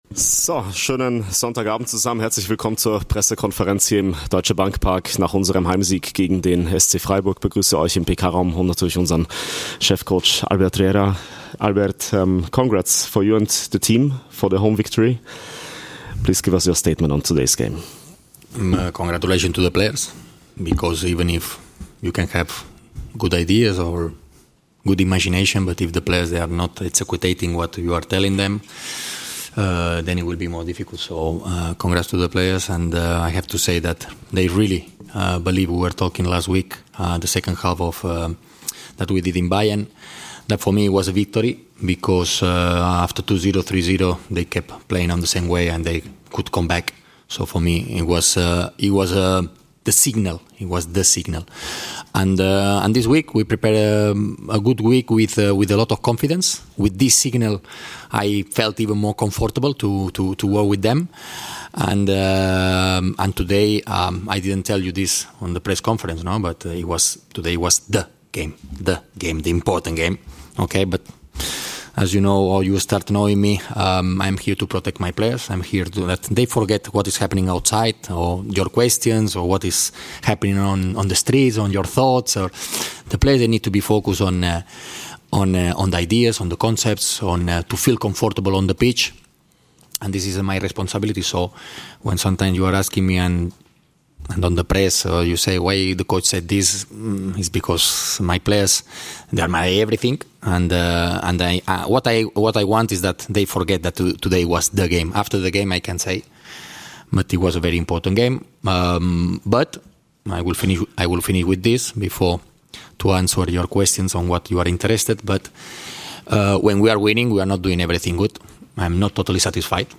Die beiden Cheftrainer Albert Riera und Julian Schuster auf der Pressekonferenz nach der 2:0-Heimsieg gegen den SC Freiburg.